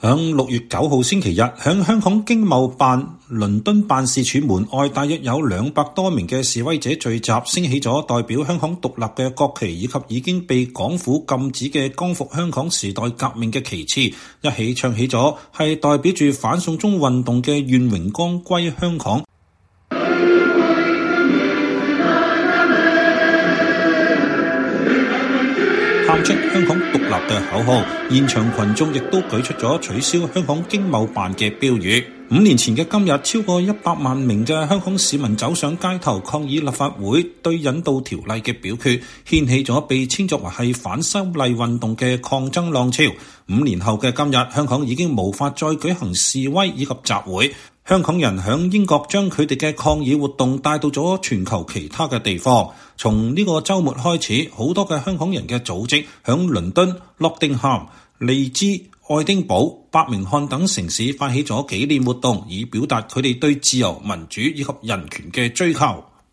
約兩百名示威者星期日(6月9日)在倫敦的香港經貿辦外舉行集會，紀念“反送中運動”五週年。他們升起了代表香港獨立的旗幟和已經被港府禁止的“光復香港，時代革命”旗，還高唱代表著“反送中運動”的《願榮光歸香港》，並喊出“香港獨立”等口號。